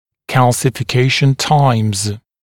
[ˌkælsɪfɪ’keɪʃn taɪmz][ˌкэлсифи’кейшн таймз]сроки минерализации